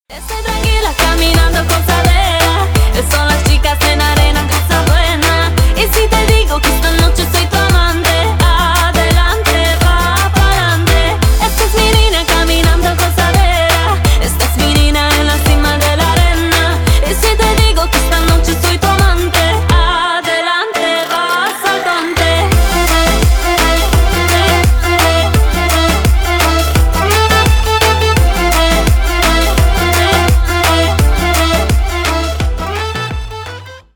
Танцевальные
латинские